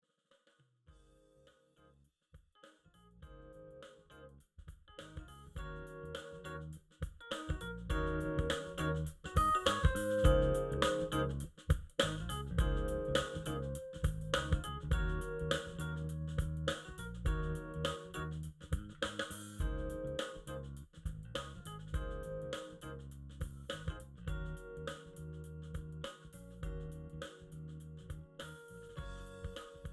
This is an instrumental backing track cover.
• Key – D♭
• Without Backing Vocals
• With Fade